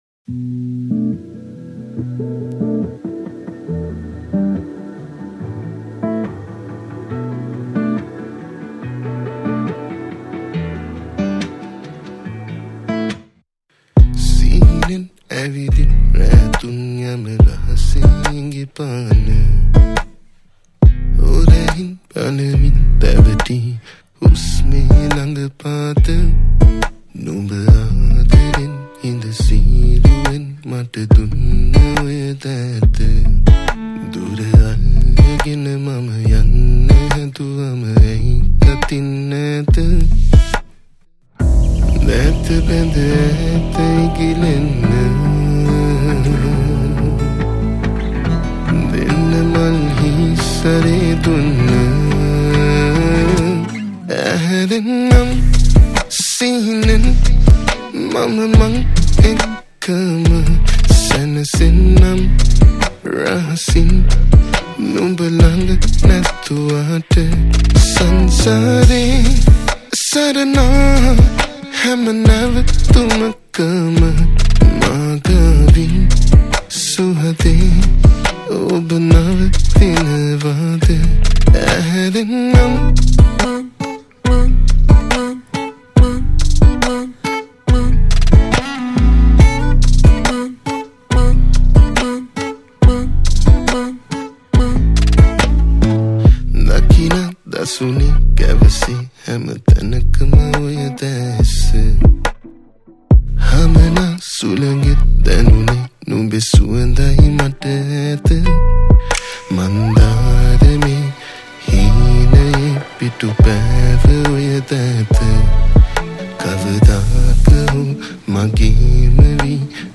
Guitars
Vocals and guitars recorded